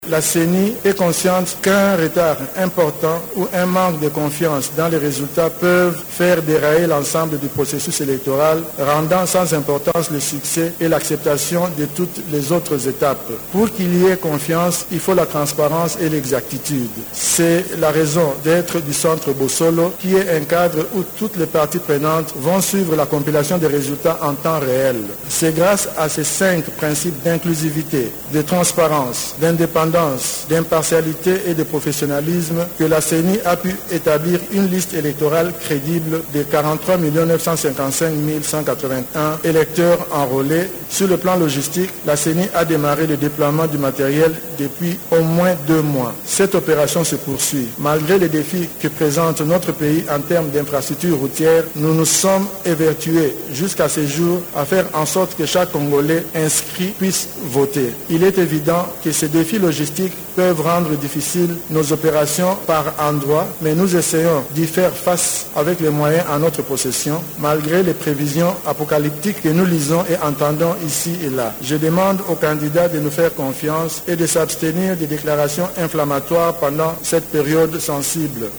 Le président de la CENI, Denis Kadima, a affirmé le mardi 19 décembre dans la soirée que les élections sont un « moment de communion et de vérité, mais une vérité que nous devons vivre ensemble ».